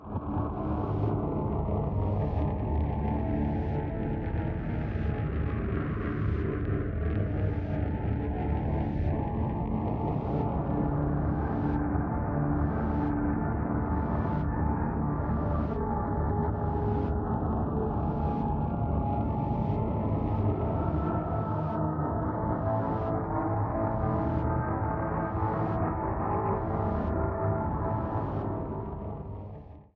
Ripped from game
Fair use music sample